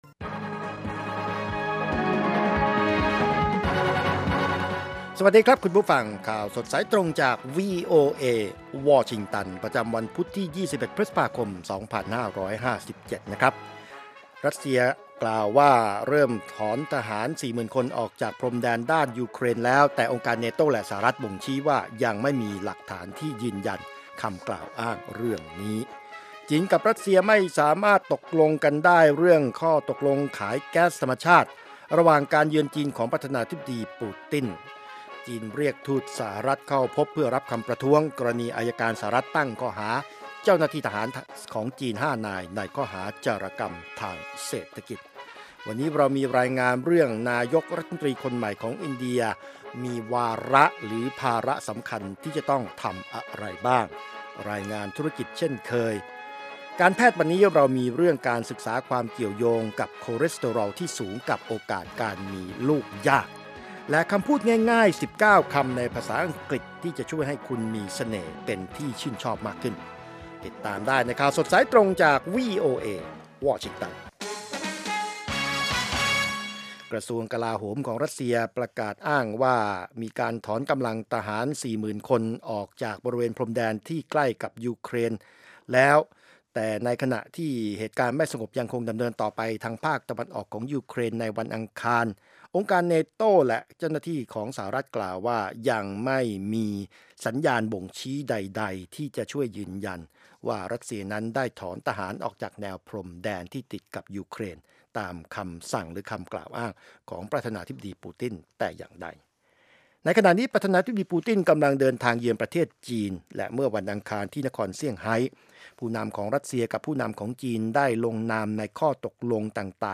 ข่าวสดสายตรงจากวีโอเอ ภาคภาษาไทย 8:30–9:00 น.